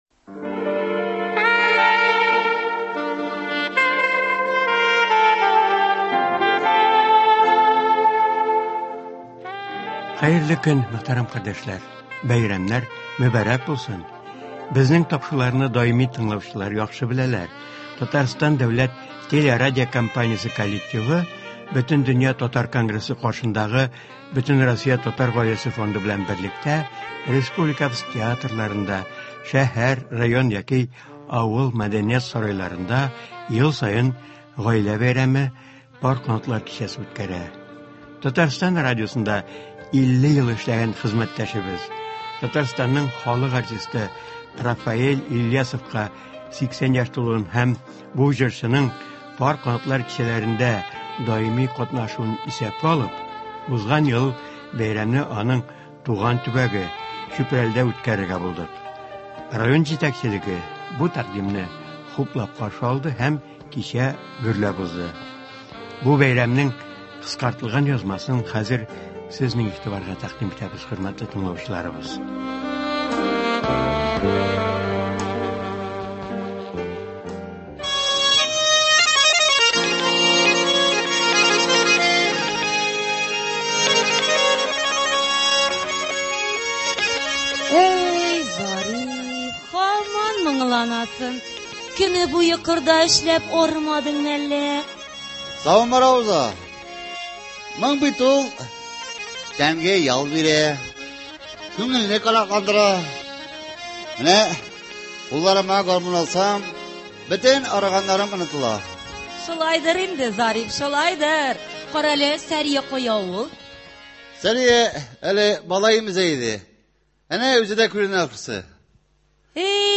Тыңлаучыларга шушы кичәнең язмасы тәкъдим ителә.